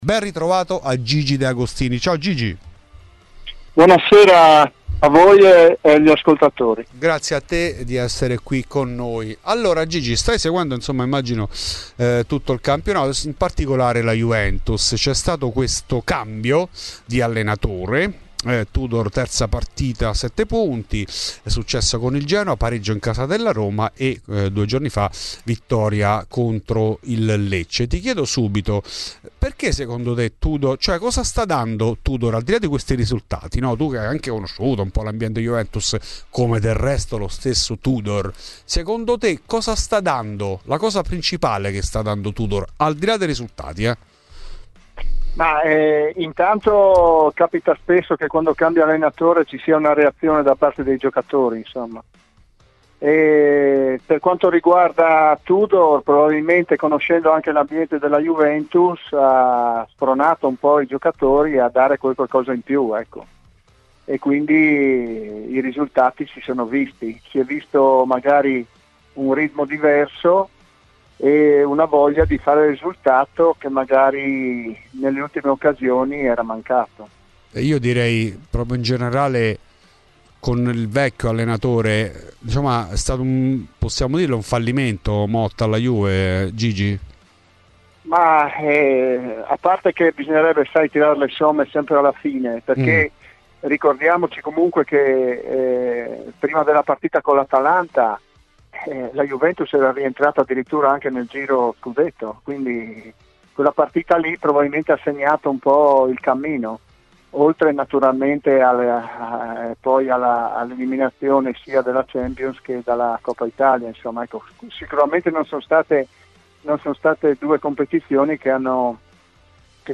Sul fronte del mercato, non si placano le voci sull'addio di Cambiaso, destinazione City. Questi ed altri i temi trattati in ESCLUSIVA a Fuori di Juve da Gigi De Agostini.